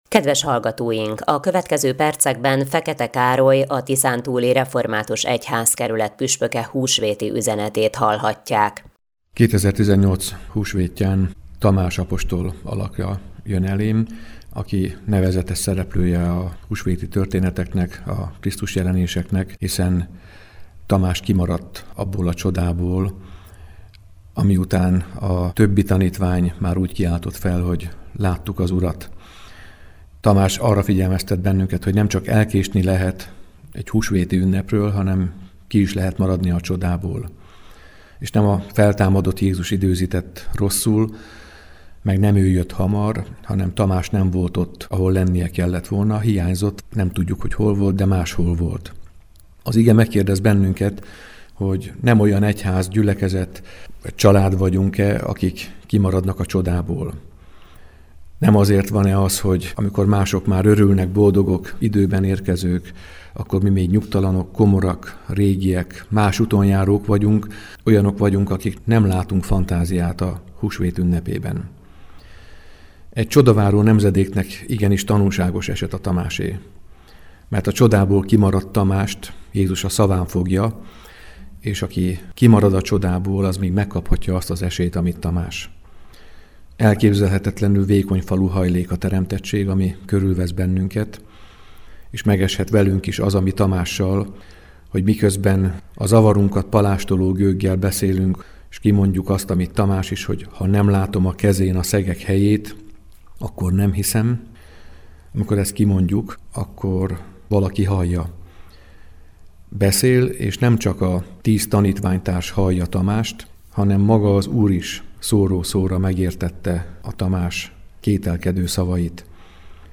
7-22-husvetiuzi-feketek-puspok.mp3